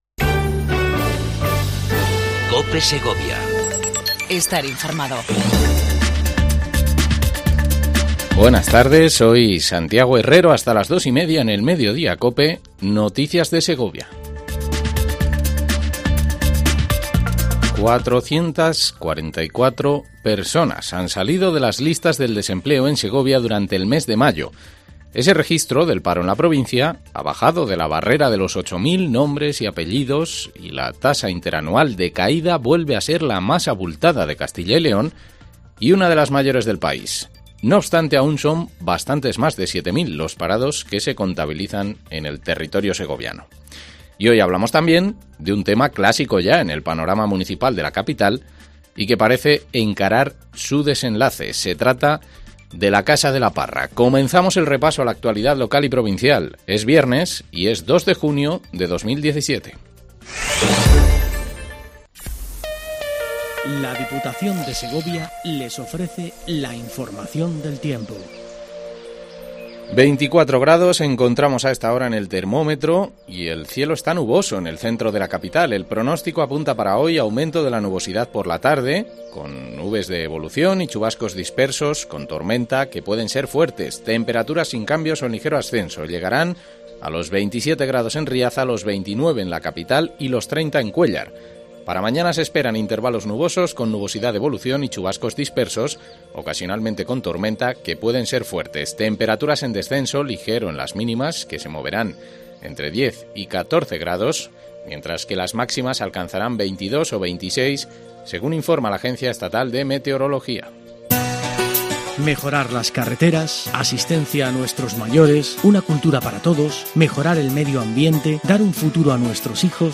INFORMATIVO MEDIODIA COPE EN SEGOVIA 02 06 17